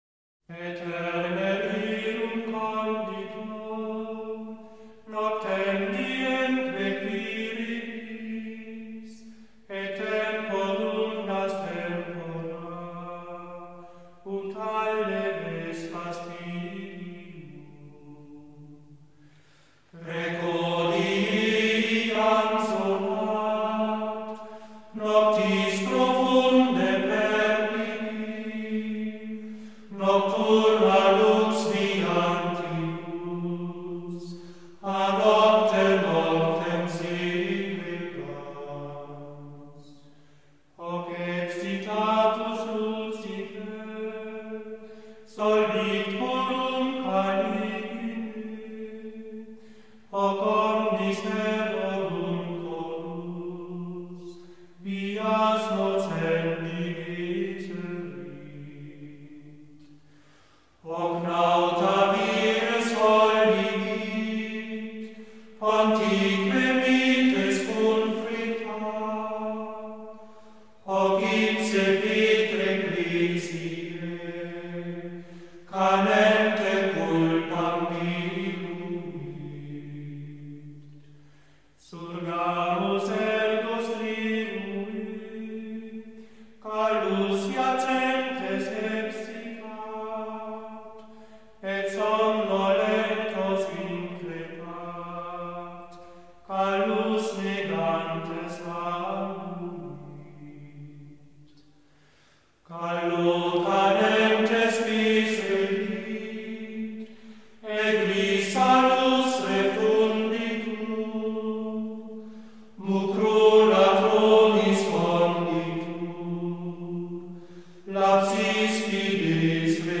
vers. ritmica